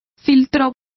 Complete with pronunciation of the translation of filters.